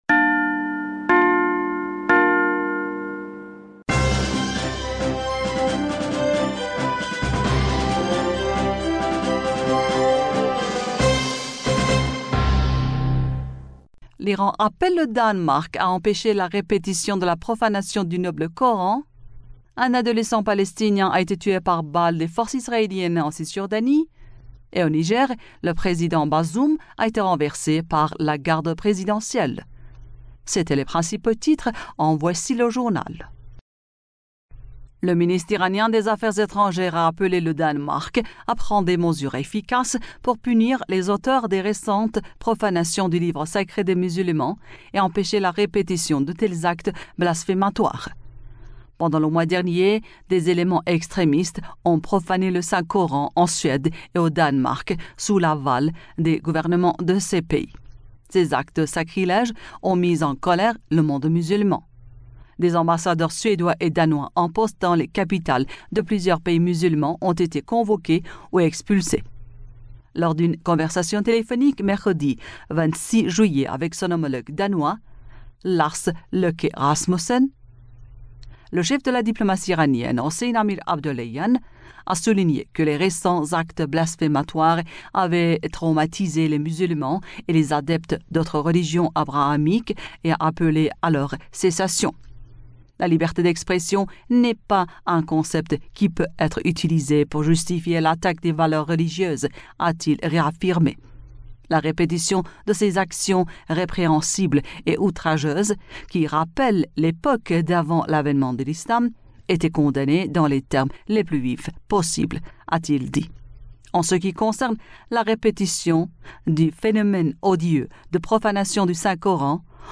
Bulletin d'information du 27 Juillet 2023